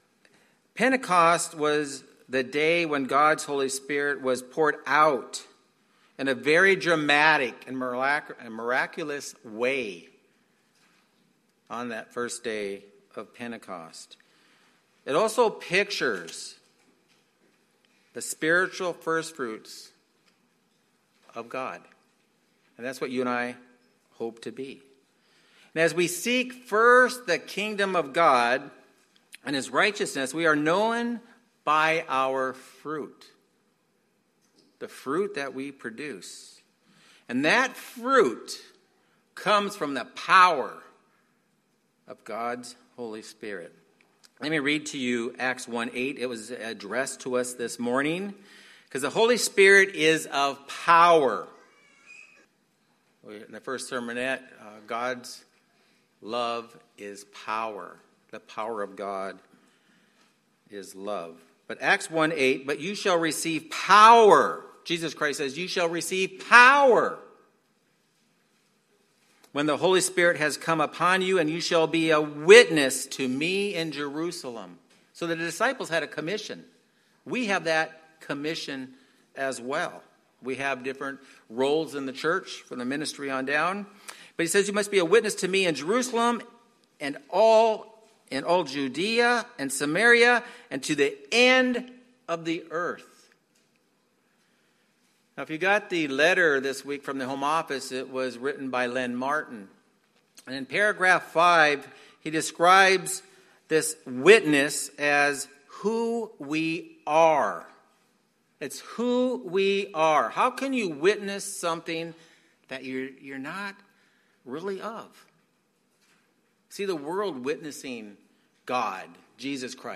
Sermon
Given in Phoenix Northwest, AZ